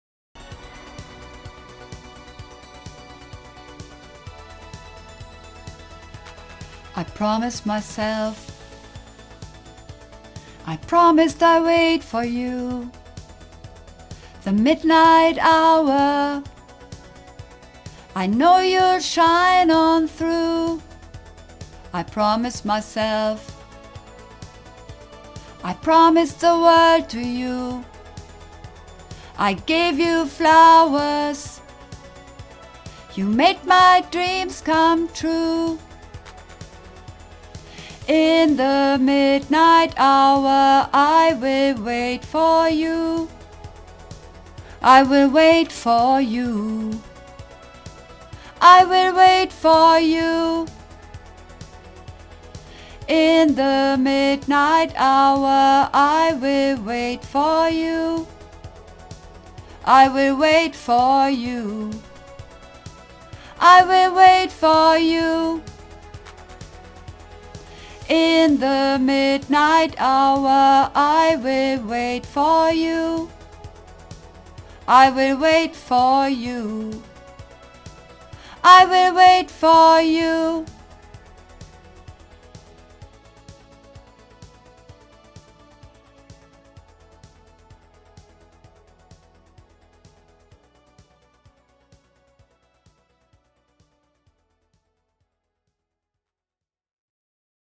Übungsaufnahmen - I Promised Myself